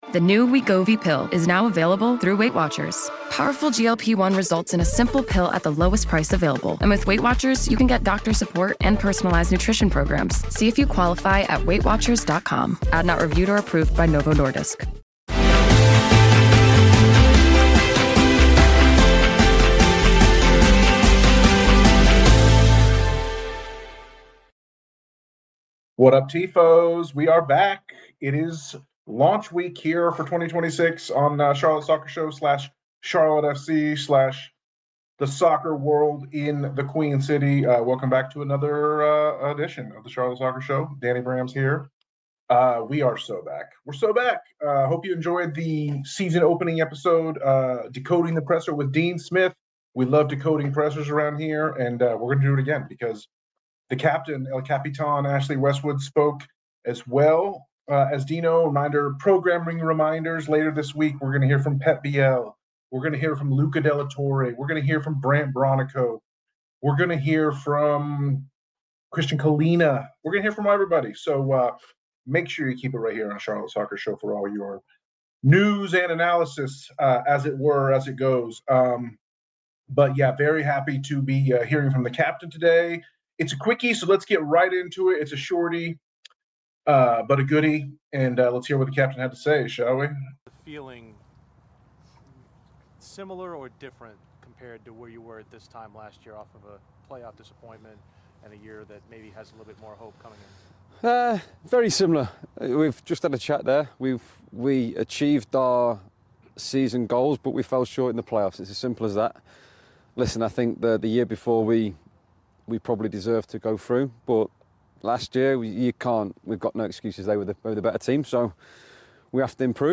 It may've been freezing cold out but that didn't stop Charlotte FC captain Ashley Westwood from his appointed rounds meeting with local journalists and podcasters as the Crown opens its 2026 campaign.